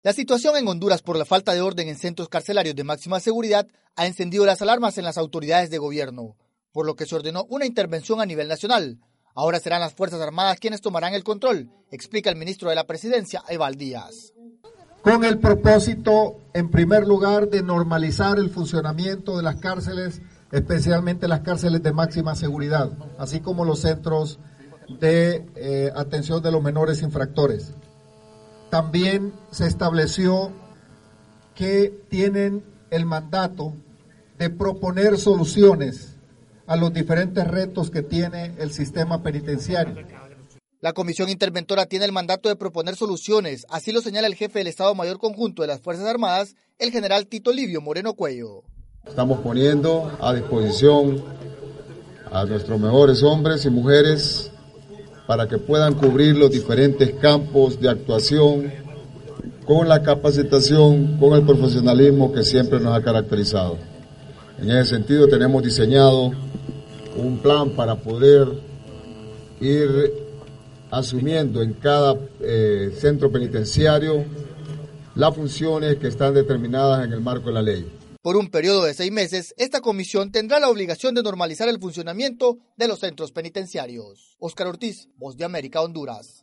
VOA: Informe de Honduras